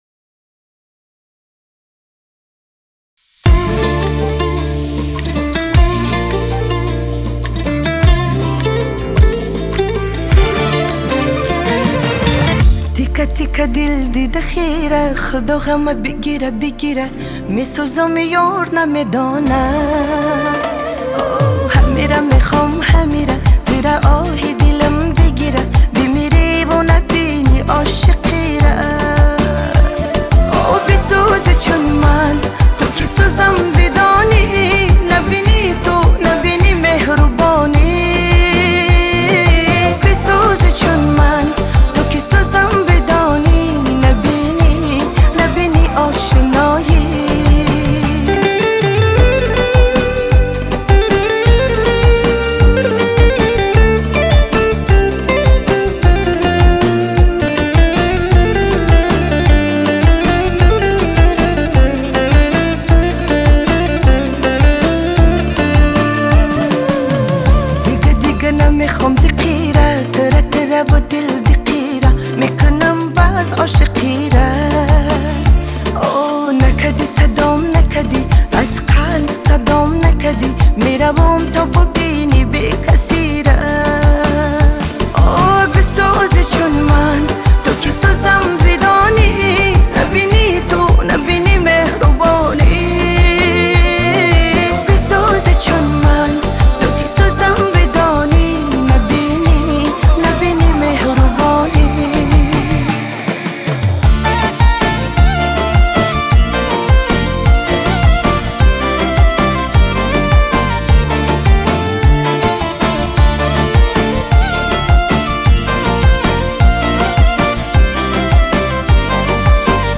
Музыка / 2026-год / Таджикские / Поп